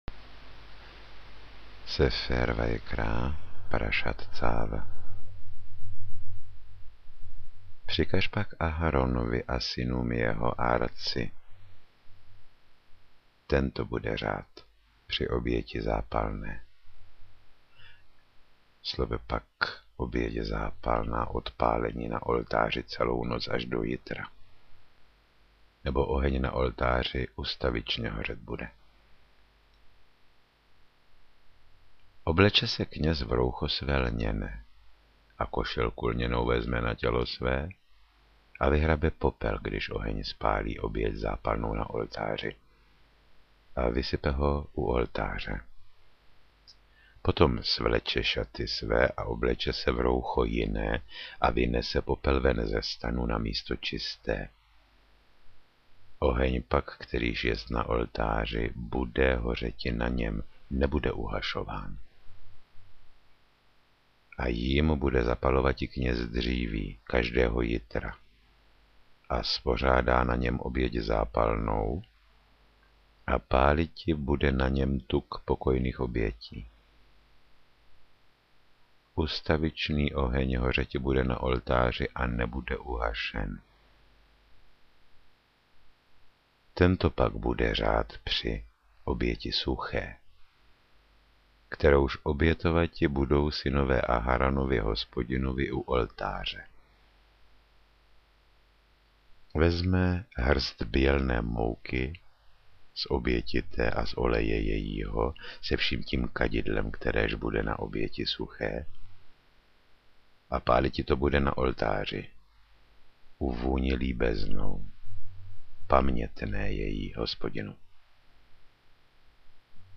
Třetí kniha Mojžíšova – Vajikra – Leviticus – Audio kniha
Namluvení Tóry Jaroslavem Achabem Haidlerem.